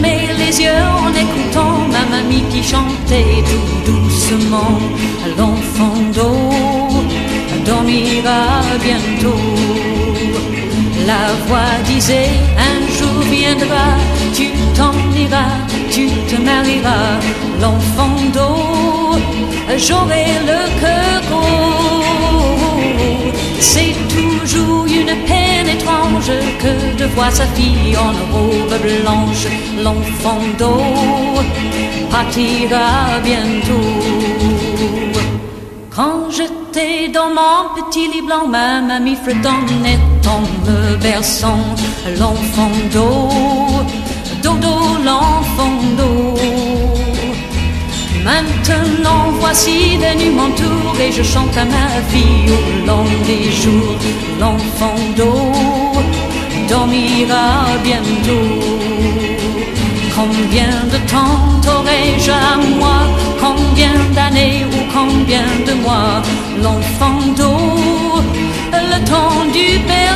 オールディーズ・アイドルのロックンロールな名曲を揃えています！
ザクザクとした響きが気持ちいいビバップなフォーキー・ロックンロール